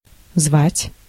Ääntäminen
IPA : /ˈɪn.vaɪt/